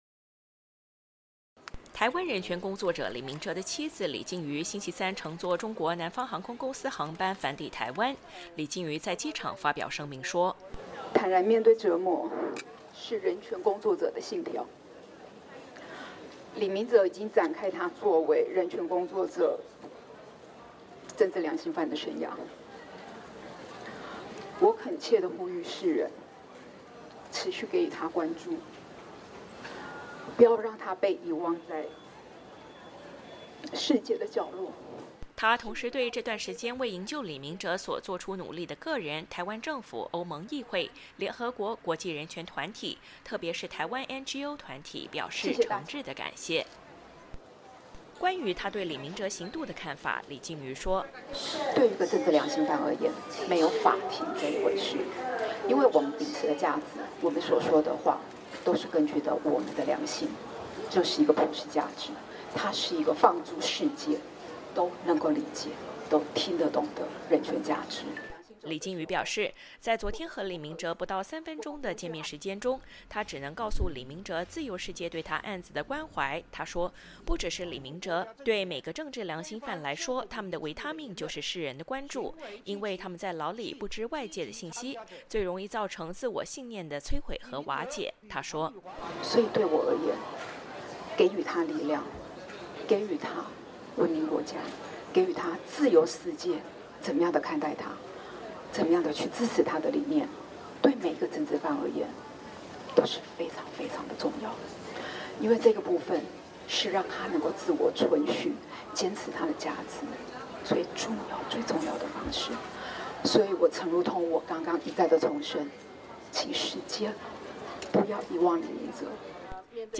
台北 —